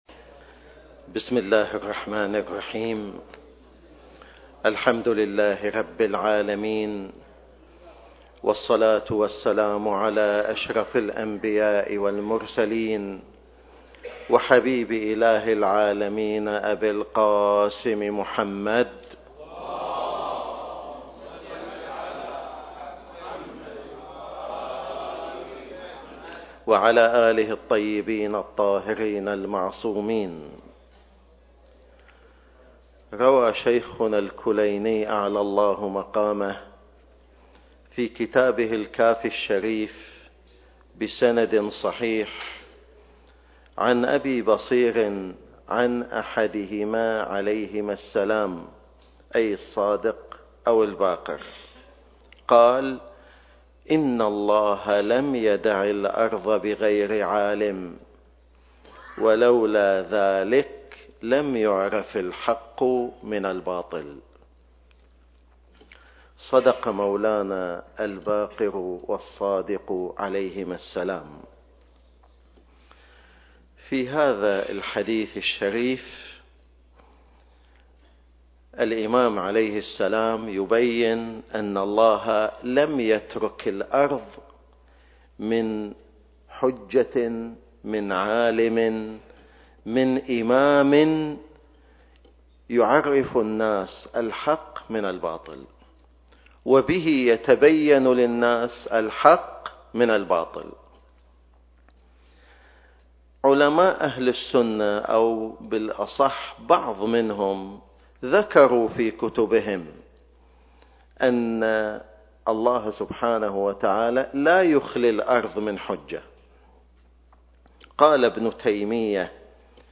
محاضرة الجمعة